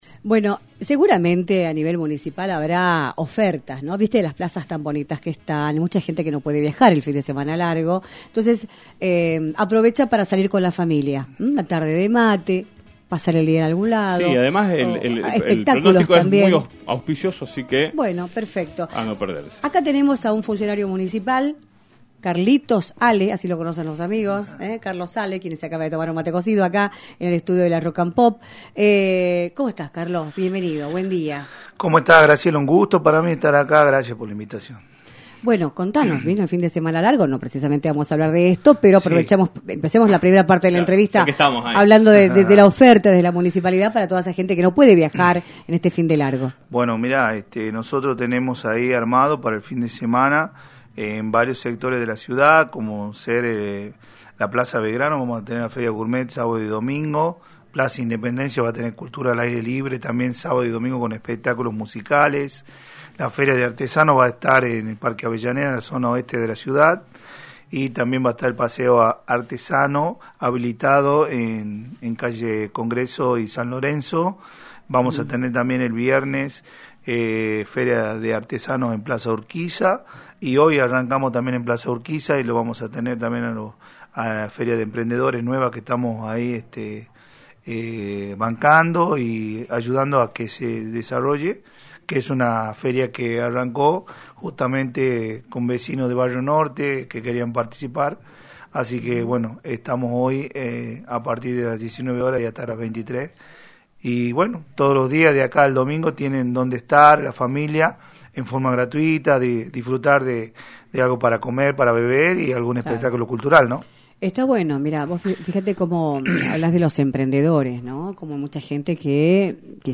Carlos Ale, Secretario de Desarrollo Social y Cultural de San Miguel de Tucumán y candidato a Concejal, visitó los estudios de “Libertad de Expresión” para informar los eventos que tiene planificada la Municipalidad de la Capital para este fin de semana largo y para analizar el escenario político y electoral de la provincia.